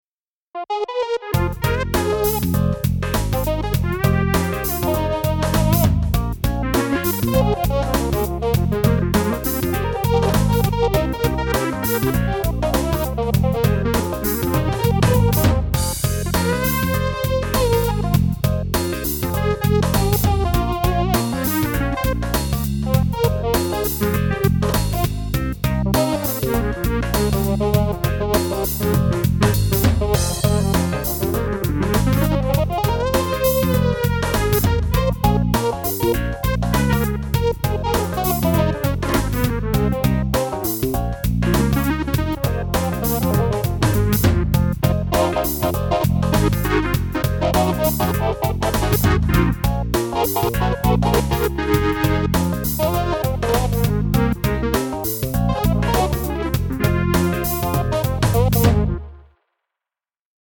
ManyOne comes with a wide range of sounds in four categories: electric pianos, ensembles, organs, and waveforms (which include classic analog waves) that are all selectable from the easy to use sound browser.
Demo made with ManyOne and with a drum kit from the Manytone FreeZone